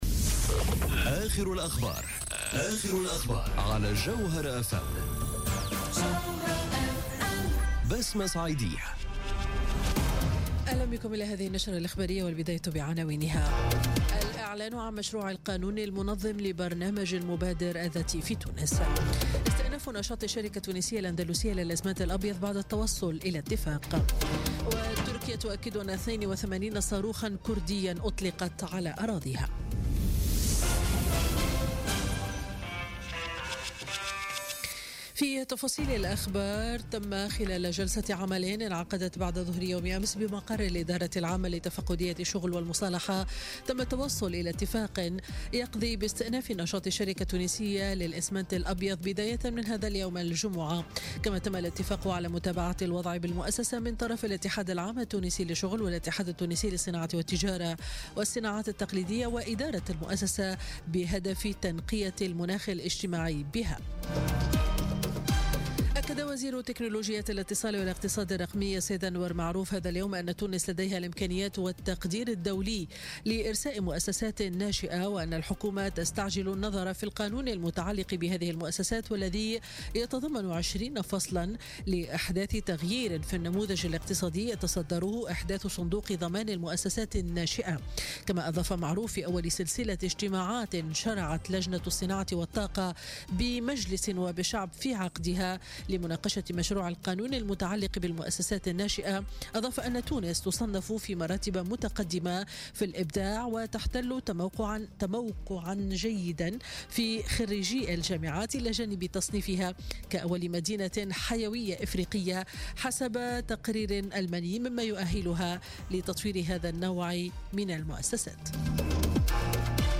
نشرة أخبار منتصف النهار ليوم الجمعة 2 فيفري 2018